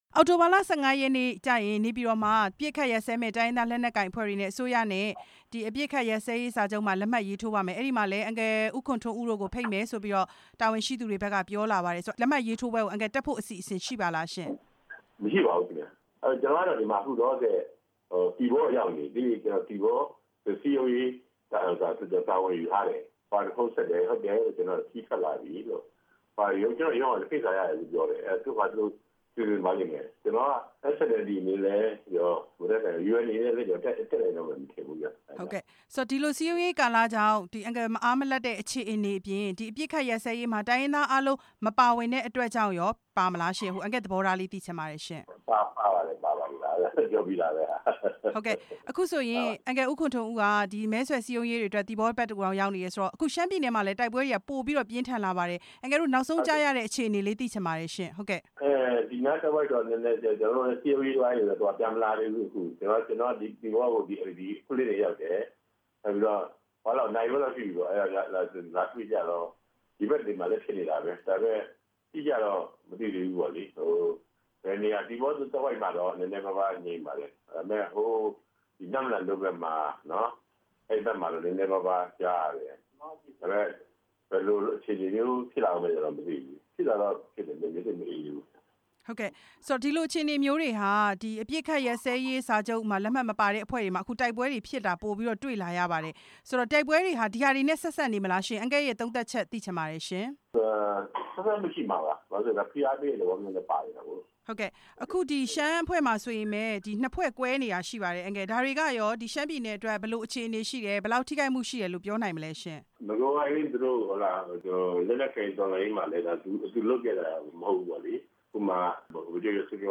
ကျားခေါင်းပါတီ (SNLD) ဥက္ကဌ ဦးခွန်ထွန်းဦးနဲ့ မေးမြန်းချက်